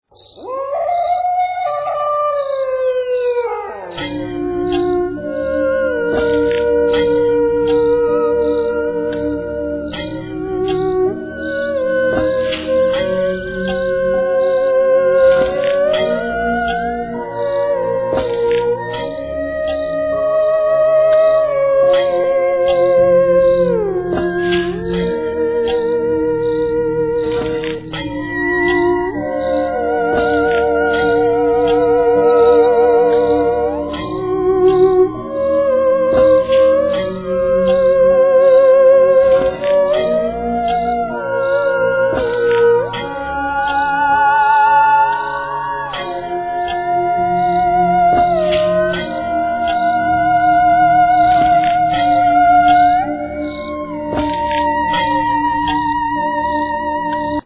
Theremin, Midi-theremein, Sampling, Bass
Flute, Alt & Bass Flute, Clarinet, Bass clalinet
Violin samples
Double bass
Vocals
Guitar
Drums, Keyboards